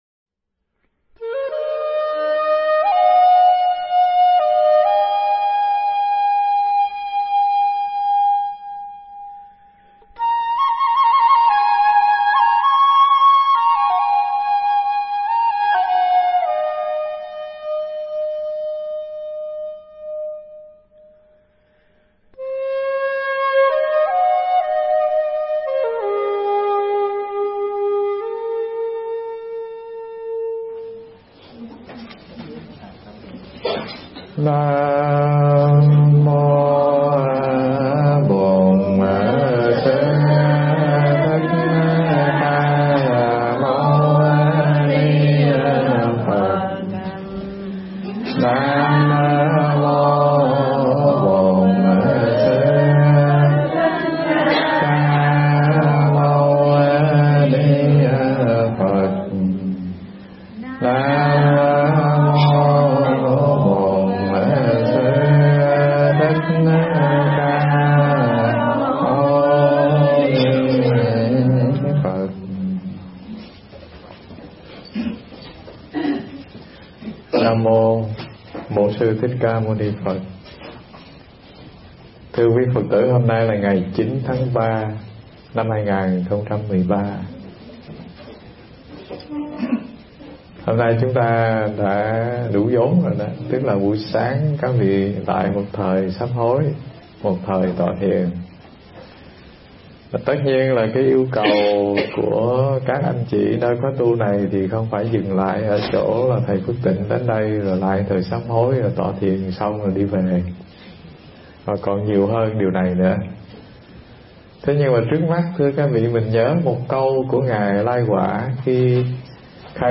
Nghe Mp3 thuyết pháp Xa Quê Từ Mấy Độ Phần 1
Mp3 pháp thoại Xa Quê Từ Mấy Độ Phần 1